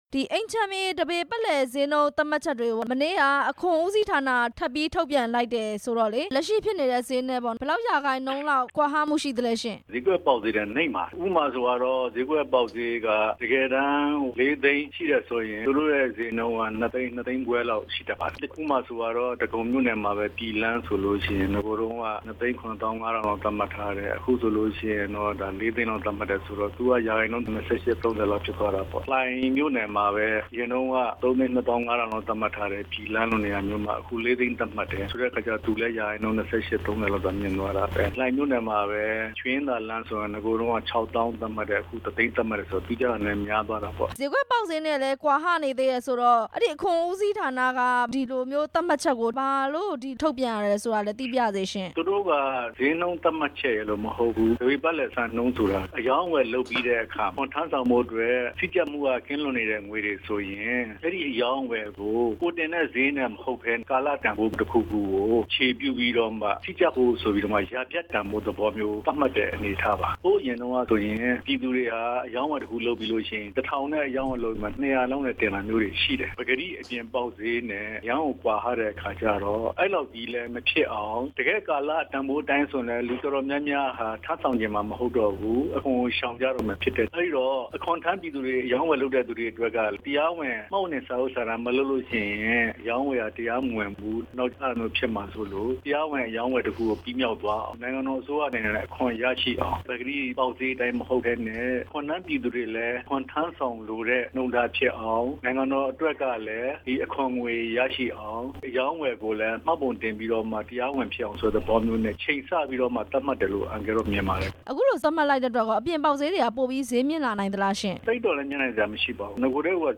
ရန်ကုန်မြေဈေးနှုန်း တိုးမြှင့်သတ်မှတ်လိုက်တဲ့အပေါ် မေးမြန်းချက်